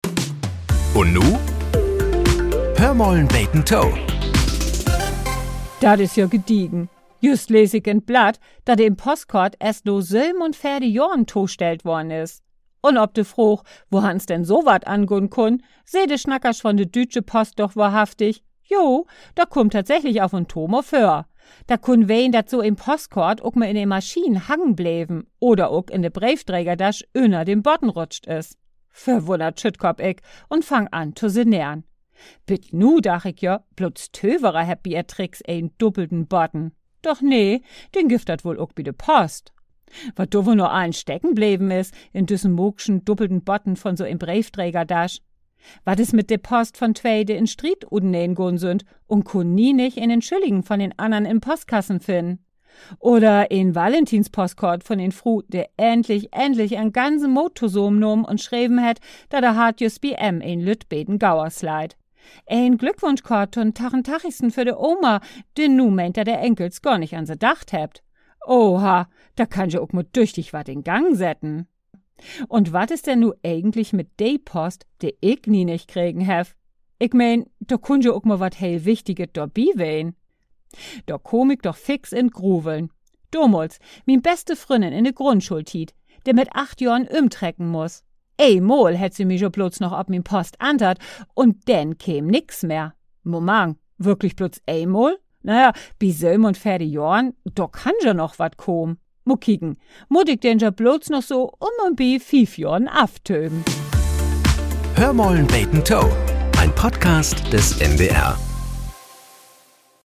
Nachrichten - 21.05.2025